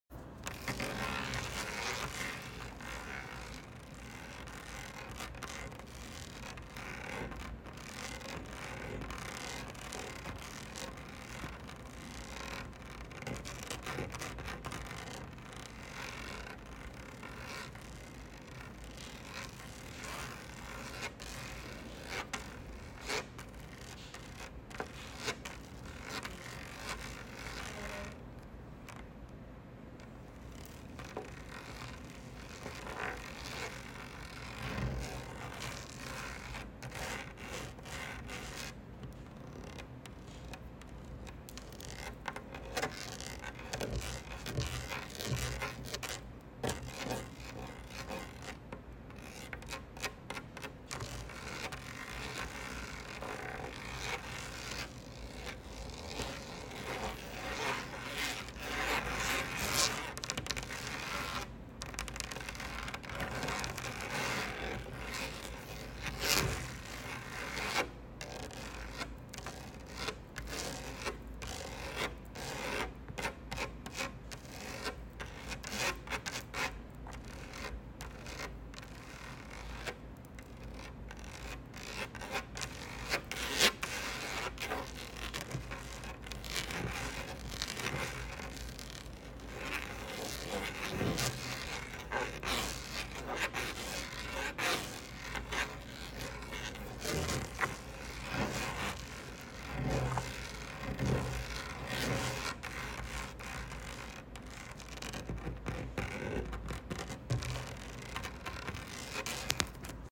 more tapping and scratching asmr